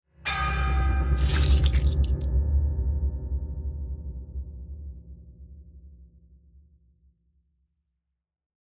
sfx_s03_剪头惊悚.ogg